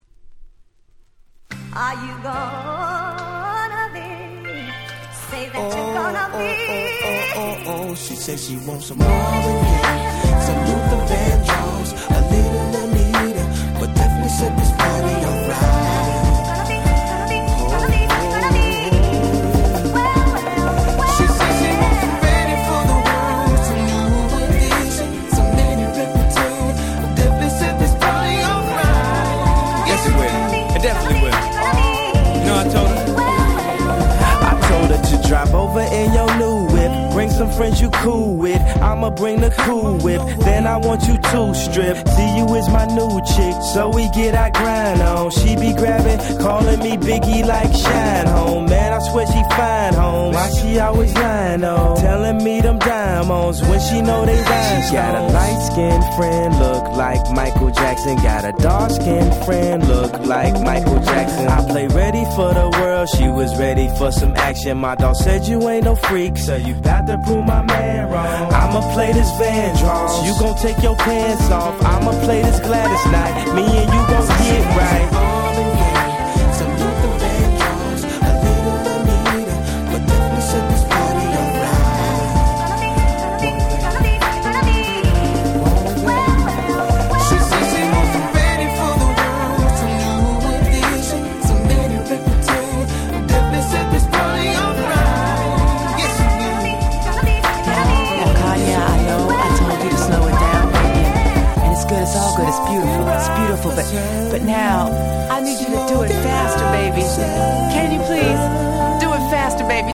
03' Super Hit Hip Hop !!